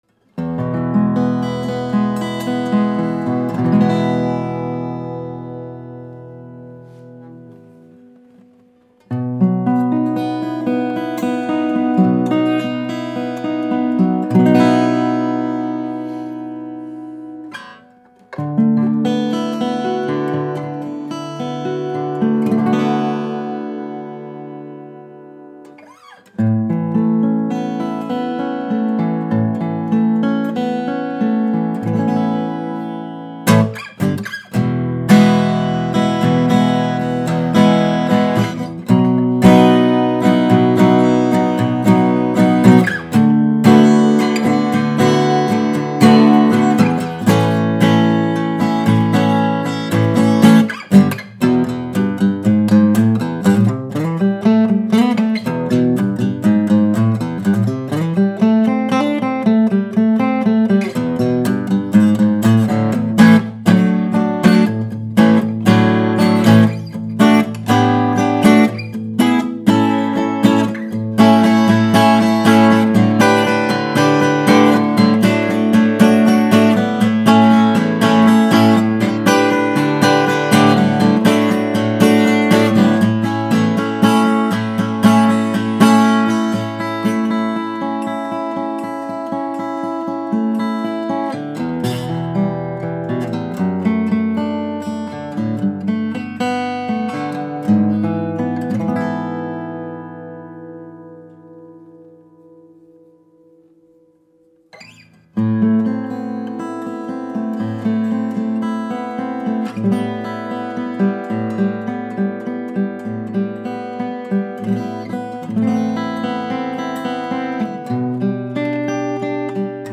The low end is tight, quick, and punchy.
The mids are clean and open with strong note separation.
Lots of definition and sparkle without turning brittle when you dig in.
Overall this is a clear, airy, and articulate 00 with extra body and headroom.
Soundimpression-Martin-00-16DBFM.mp3